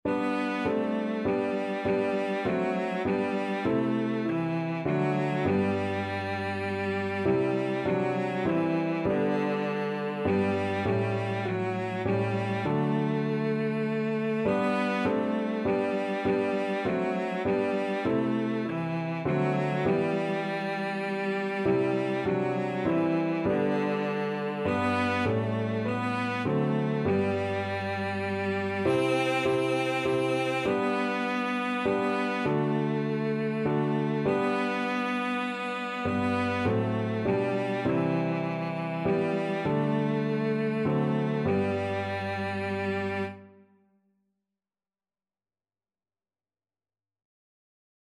Cello
Traditional Music of unknown author.
G major (Sounding Pitch) (View more G major Music for Cello )
3/4 (View more 3/4 Music)
Classical (View more Classical Cello Music)